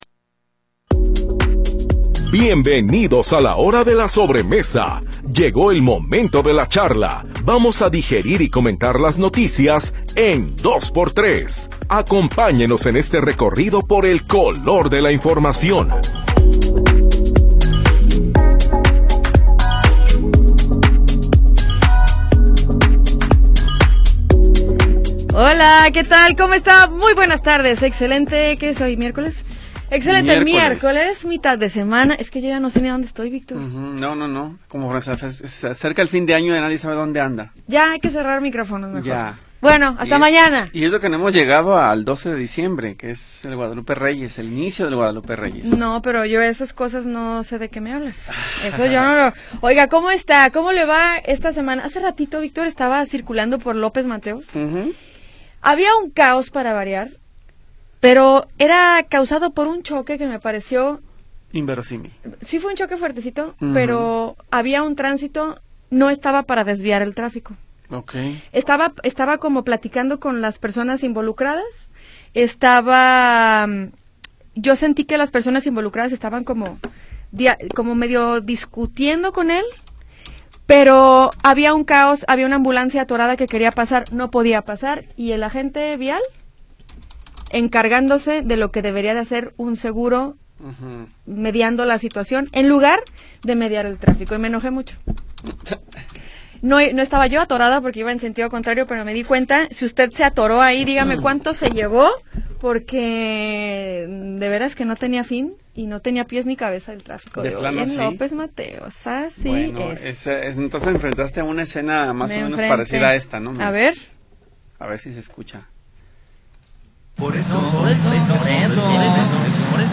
Todo el color de la noticia en una charla sabrosa después de la comida.